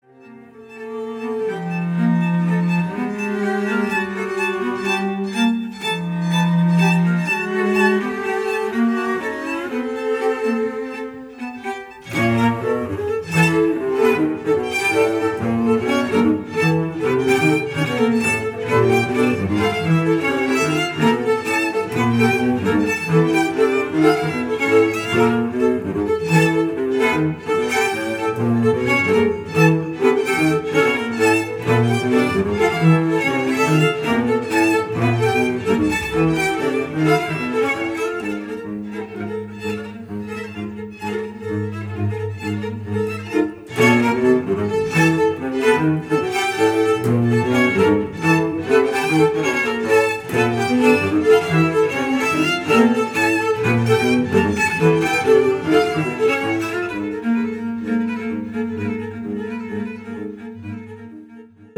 Genre : Jazz